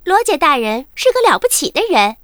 文件 文件历史 文件用途 全域文件用途 Daphne_fw_03.ogg （Ogg Vorbis声音文件，长度0.0秒，0 bps，文件大小：31 KB） 源地址:游戏语音 文件历史 点击某个日期/时间查看对应时刻的文件。